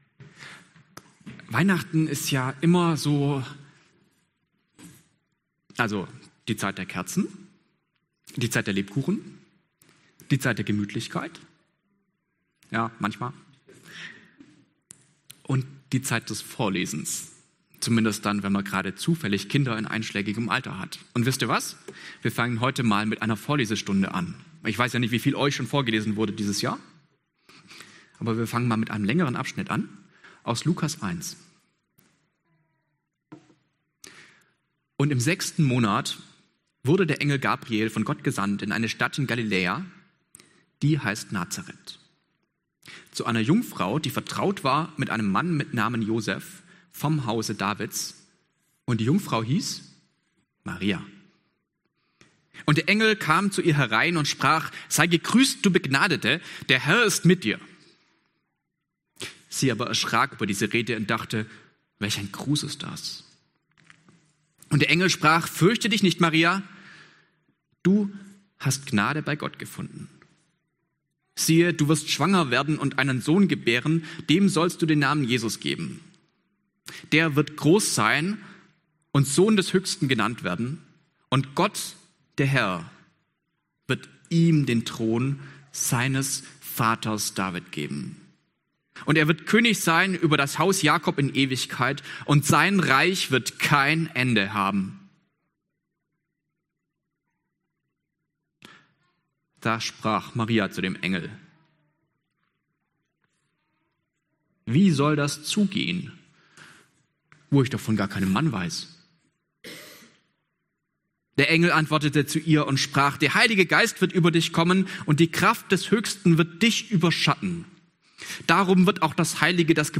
26-38 Dienstart: Gottesdienst « Gästegottesdienst